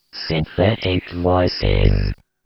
Custom Synthetic Voice file
I will produce a WAV or LPC file of a customized sentence made by synthetic voices and do one iteration.